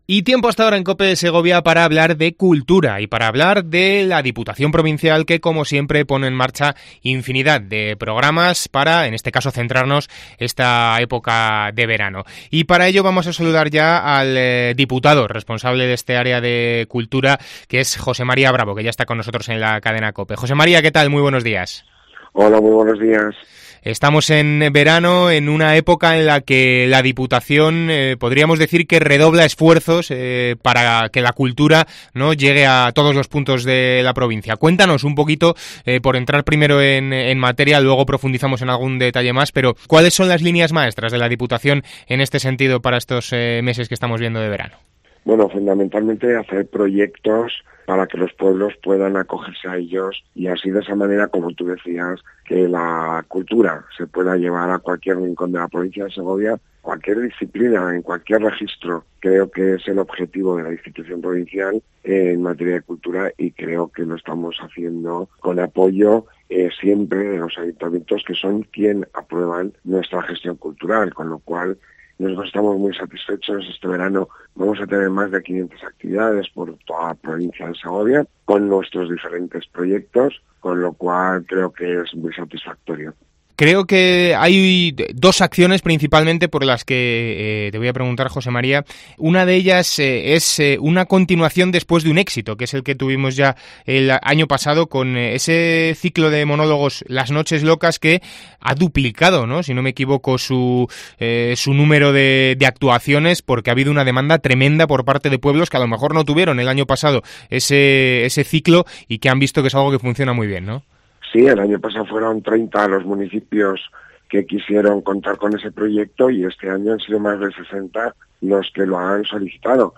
AUDIO: Entrevista
Herrera en COPE en Segovia